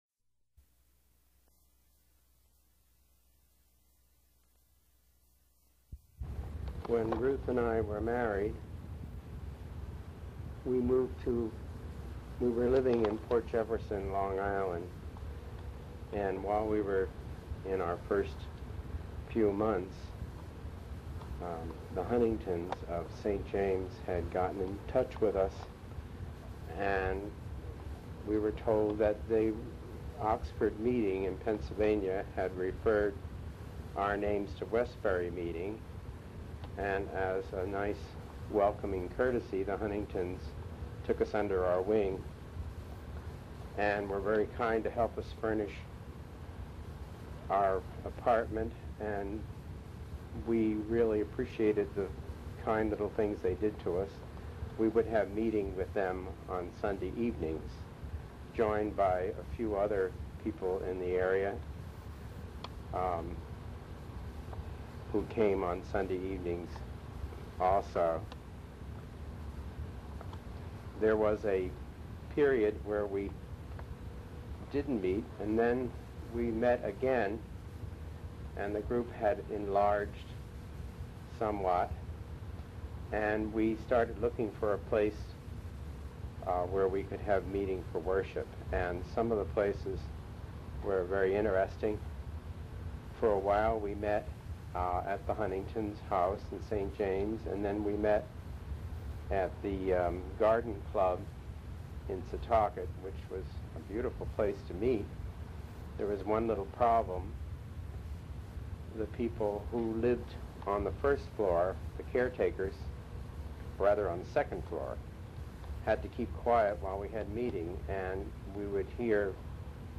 reminiscence